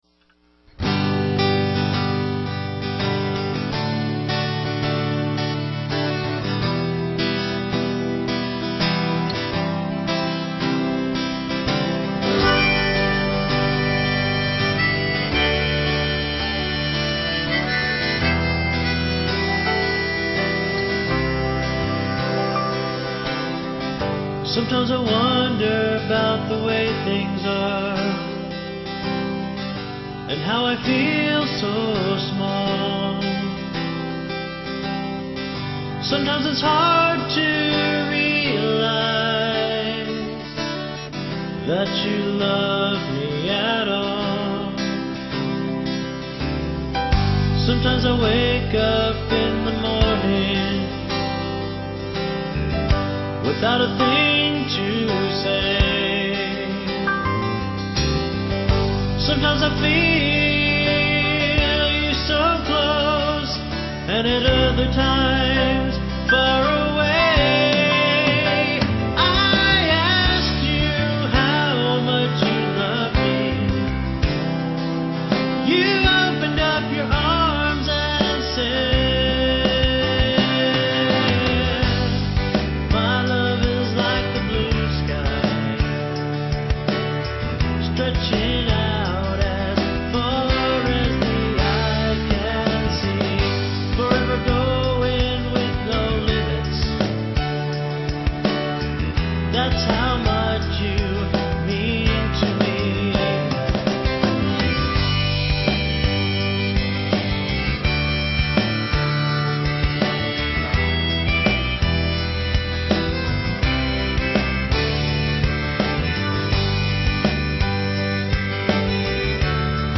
He recorded every vocal and instrumental track in this song.